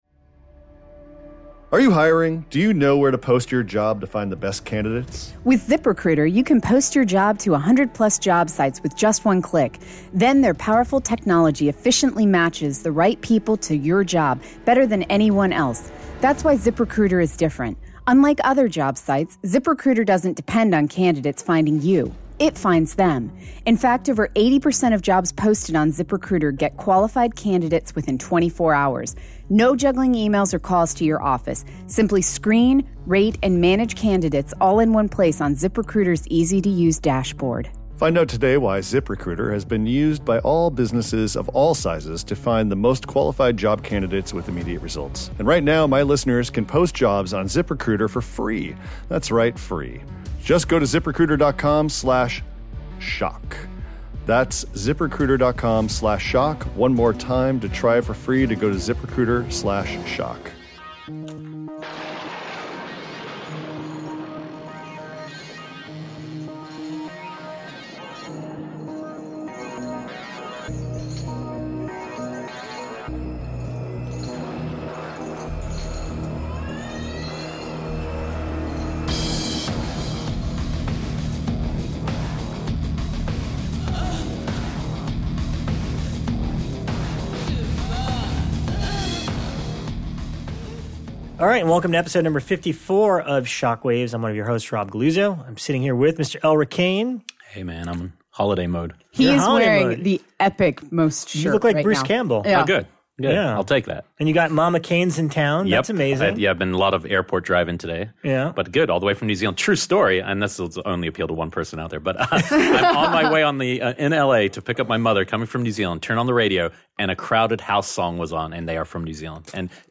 Then we welcome very special guest Jeffrey Reddick to the show!